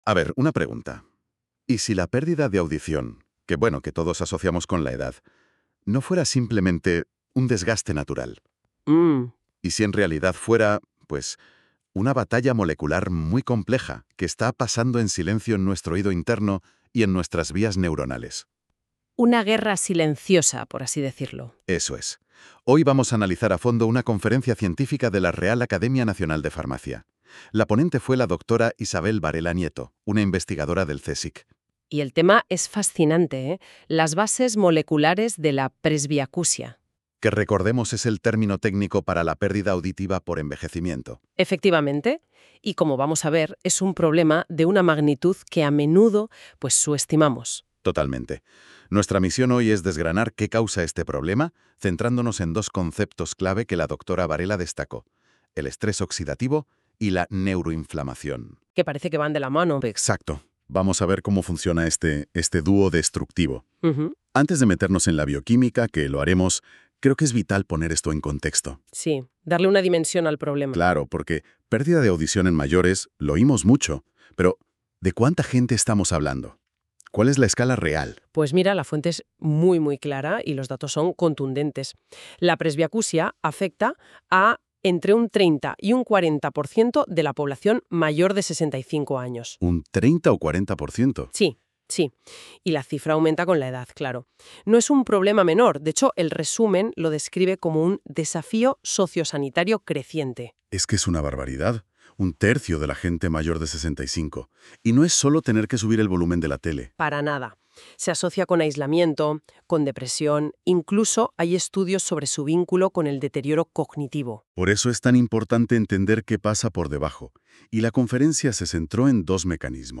Escucha el resumen de la conferencia